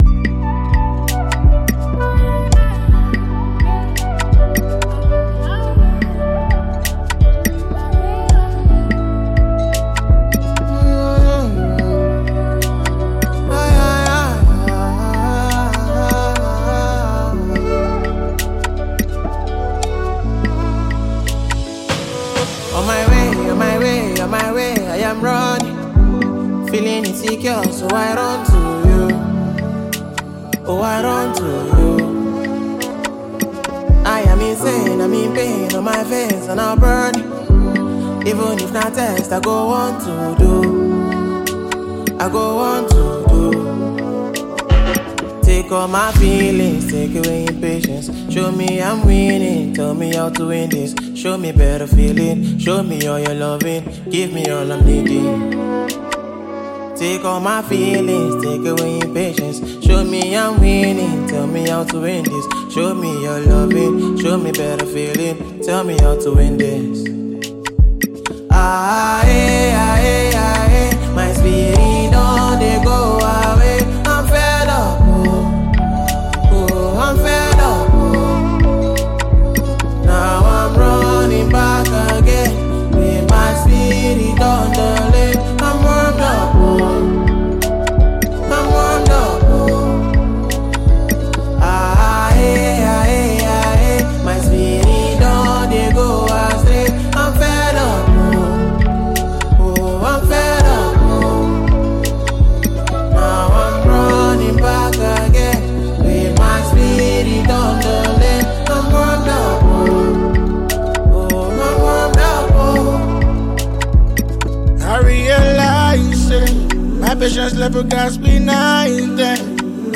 distinct voice
an inspiring tune that resonates with emotion and groove